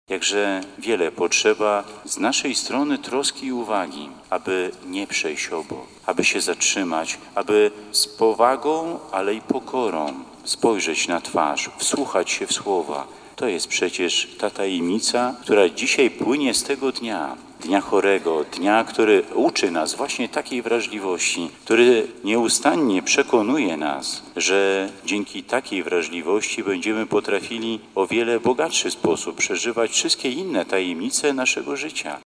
Centralne uroczystości z okazji 25. Światowego Dnia Chorego w diecezji warszawsko-praskiej odbyły się w sanktuarium Matki Bożej z Lourdes.
W czasie mszy św. bp Solarczyk przestrzegł przed obojętnością na drugiego człowieka.